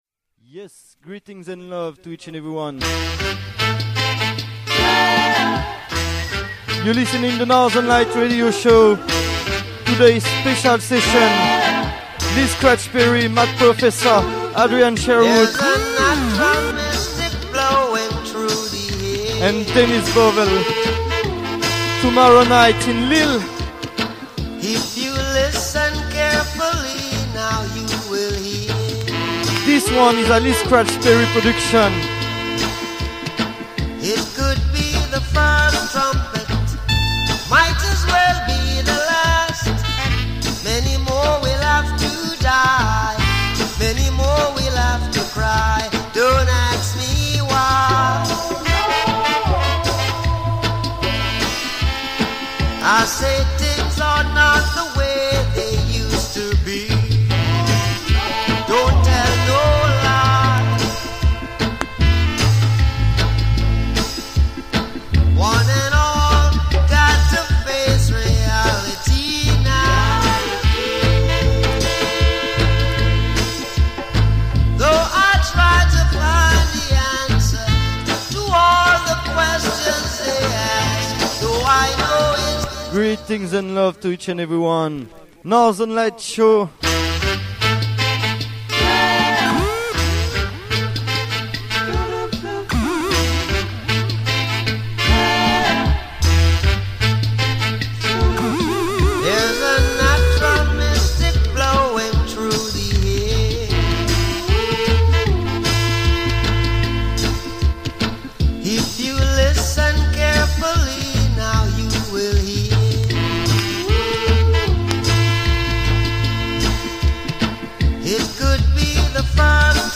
radio show
Live & Direct
Reggae-Dub-Steppa sélection, vinyls & dubplates.